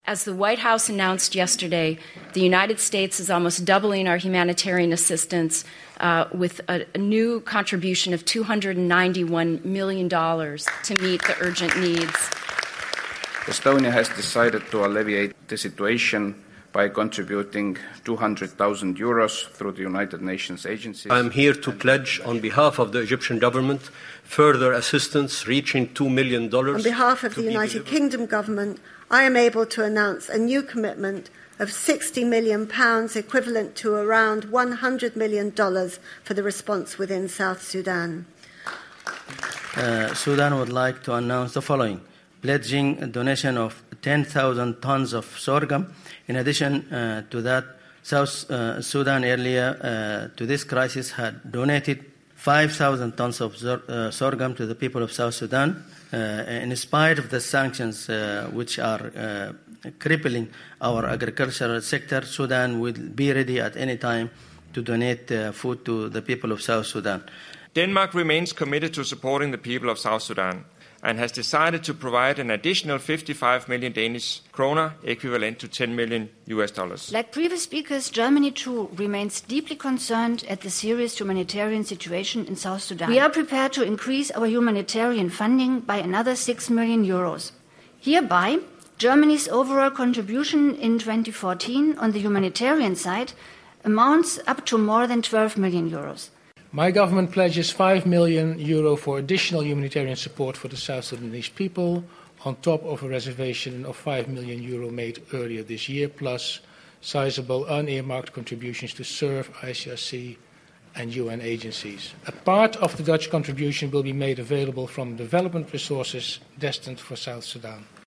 Delegates at the South Sudan donor conference in Norway announcing their pledges